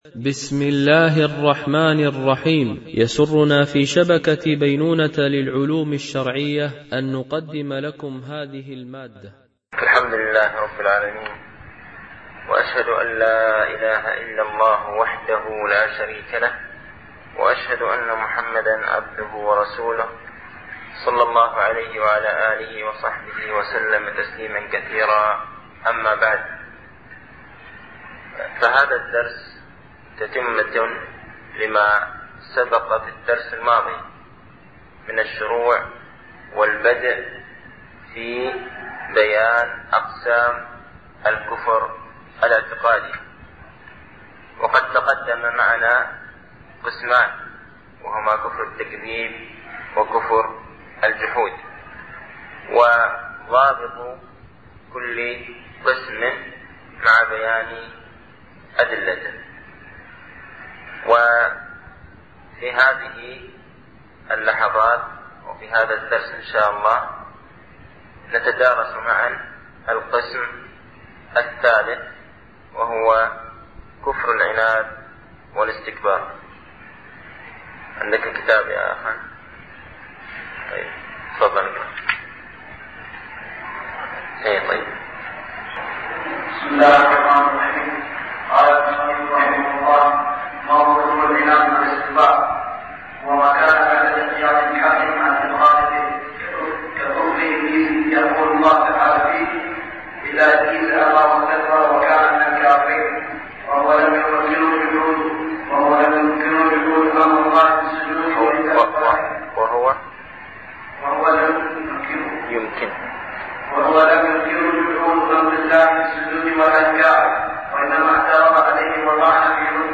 ) الألبوم: شبكة بينونة للعلوم الشرعية التتبع: 86 المدة: 32:38 دقائق (7.51 م.بايت) التنسيق: MP3 Mono 22kHz 32Kbps (CBR)